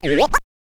scratch_kit01_06.wav